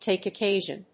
tàke occásion